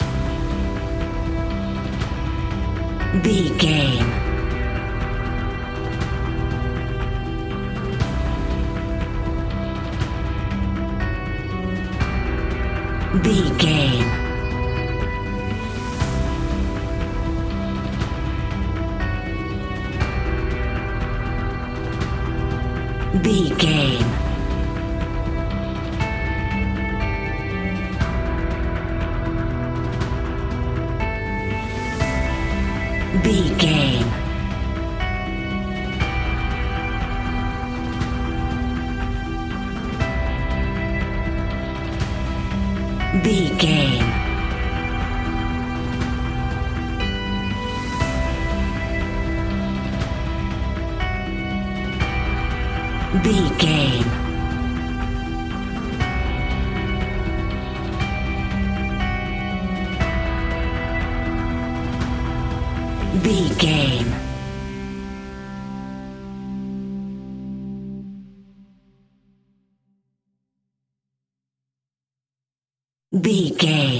Aeolian/Minor
anxious
dramatic
intense
epic
synthesiser
drums
strings
electric guitar
suspenseful
creepy
horror music